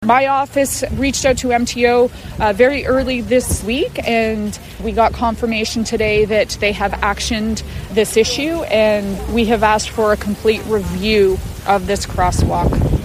Haldimand-Norfolk MPP Bobbi-Ann Brady was in attendance as well, saying her office began looking into the matter as soon as it came to their attention.